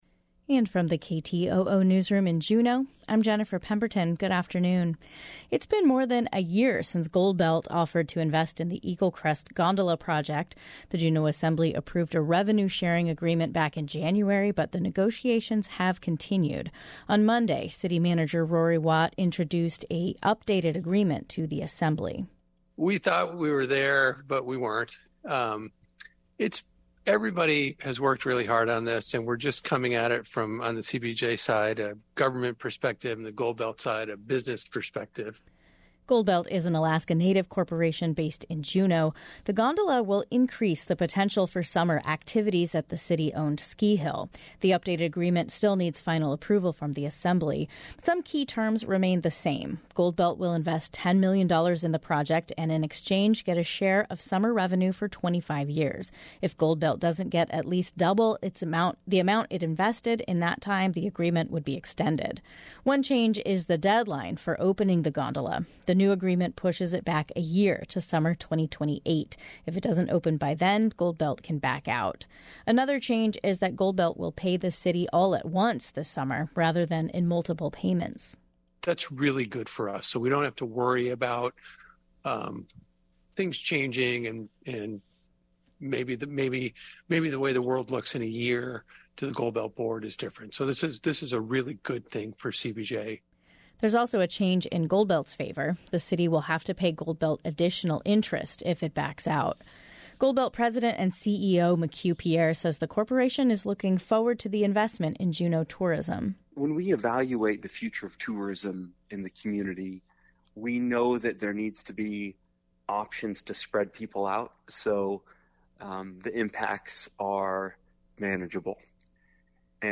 Newscast – Tuesday, Jan. 24, 2023